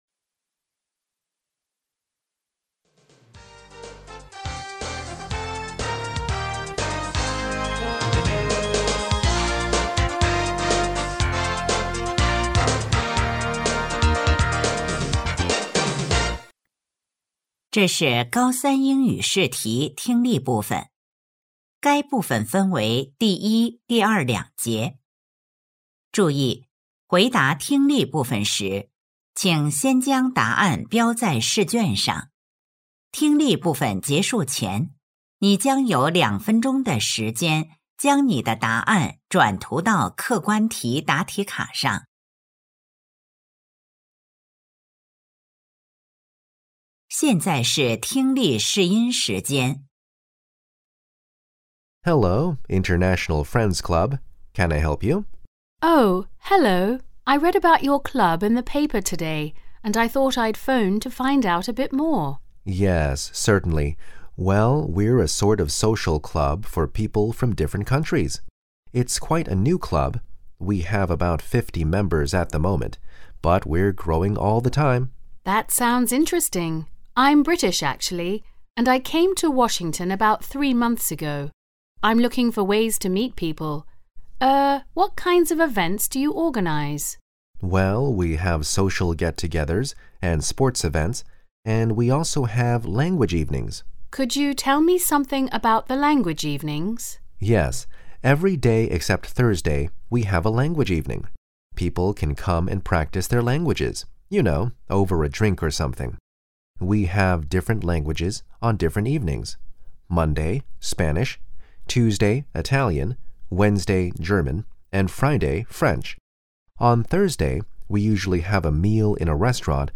2025届达州一诊英语听力.mp3